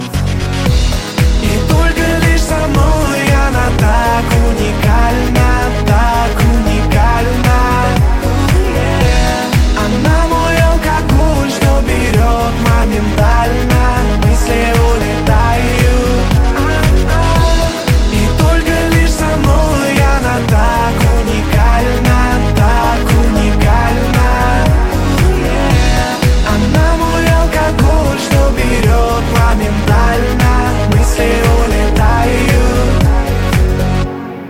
• Качество: 128, Stereo
мужской вокал
мелодичные
dance
спокойные
club
звонкие